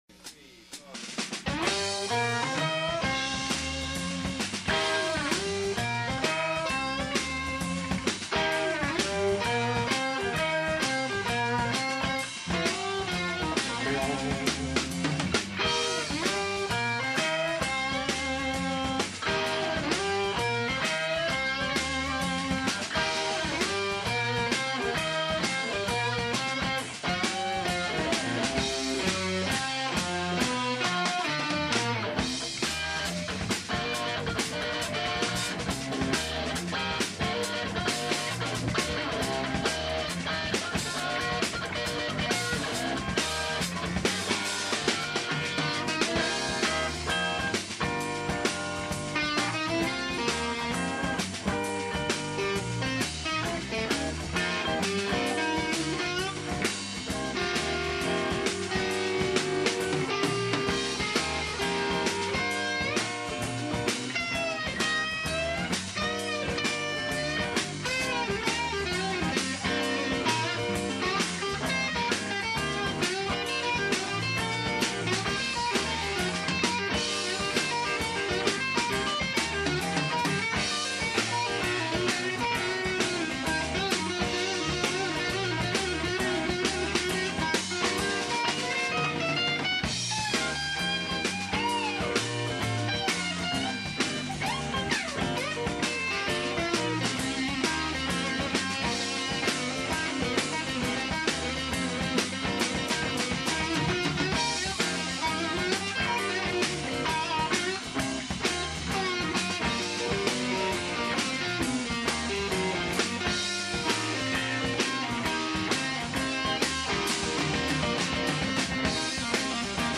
Ensemble Room Sessions approx. 1988-'90
Drums
Bass